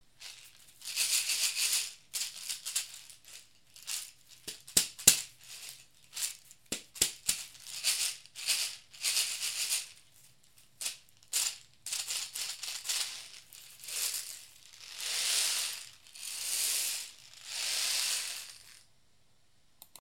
cane basket rattle
Filled with small pebbles or seeds.
Reed-shaker.mp3